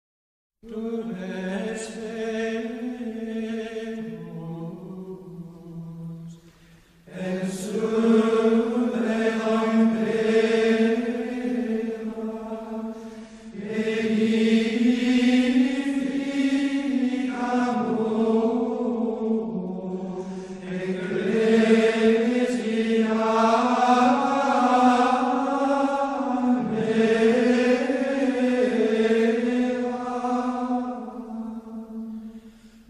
Tu-es-Petrus-comm-Canto-Gregoriano-Gregorian-Chant.mp3